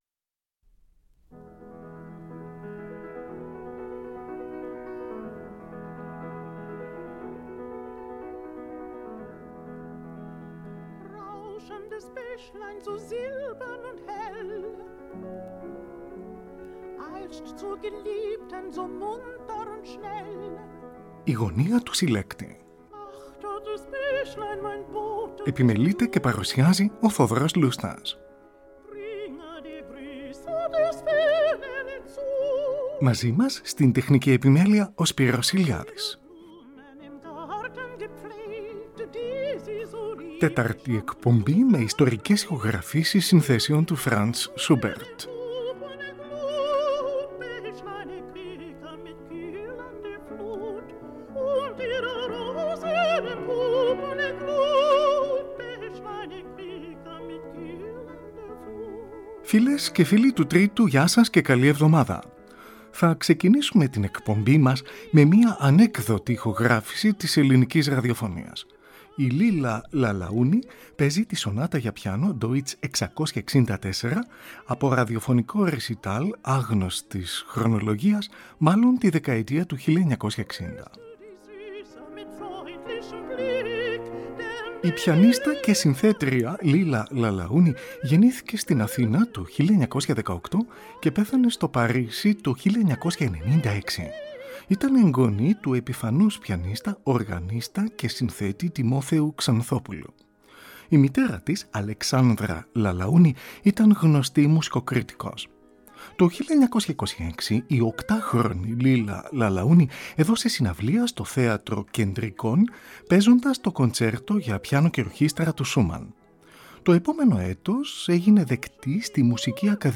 ΙΣΤΟΡΙΚΕΣ ΗΧΟΓΡΑΦΗΣΕΙΣ ΣΥΝΘΕΣΕΩΝ ΤΟΥ FRANZ SCHUBERT (ΤΕΤΑΡΤΗ ΕΚΠΟΜΠΗ)
Σονάτα για πιάνο, D.664. Παίζει η Λίλα Λαλαούνη, από ανέκδοτη ηχογράφηση της Ελληνικής Ραδιοφωνίας, μάλλον τη δεκαετία του 1960.